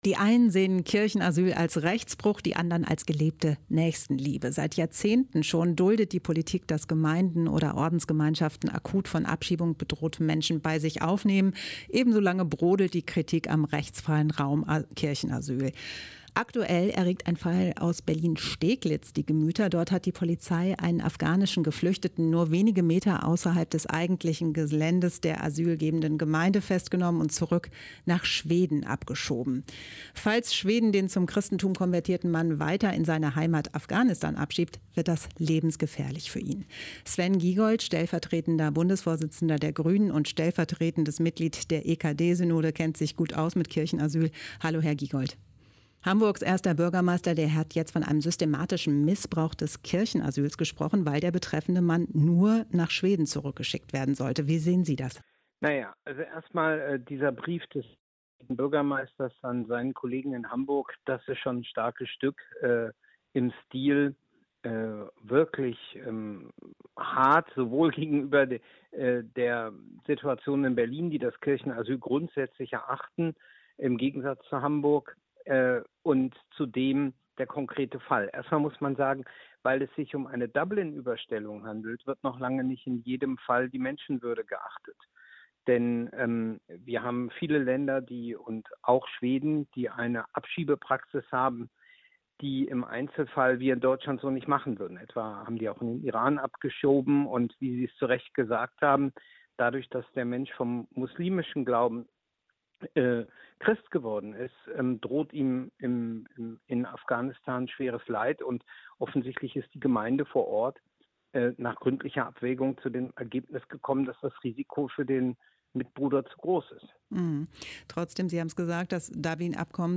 Ein Interview mit Sven Giegold (stellv. Mitglied der EKD-Synode und stellv. Bundesvorsitzender der Partei Bündnis 90/Die Grünen)